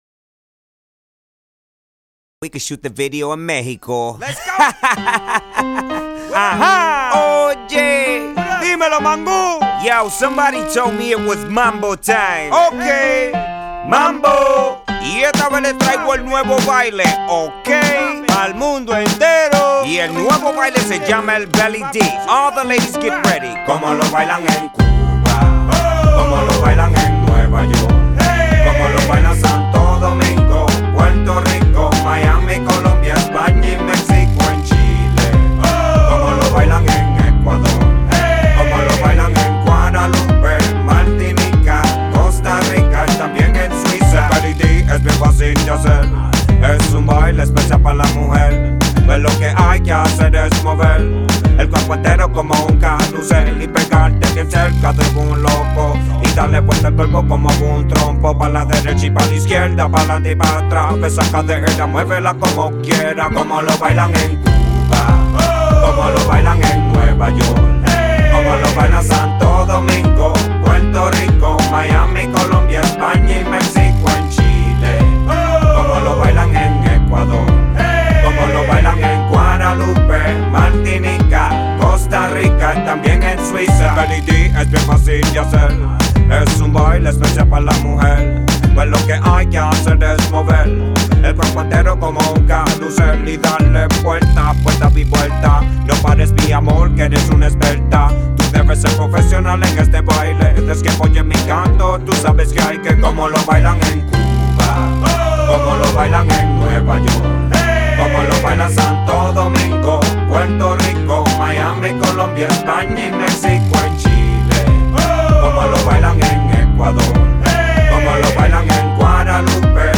Reggeaton
Reggaeton/Latin Rap